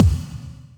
kickldk31.wav